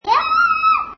sloth_feeding.mp3